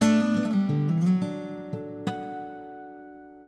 Samsung Galaxy Bildirim Sesleri - Dijital Eşik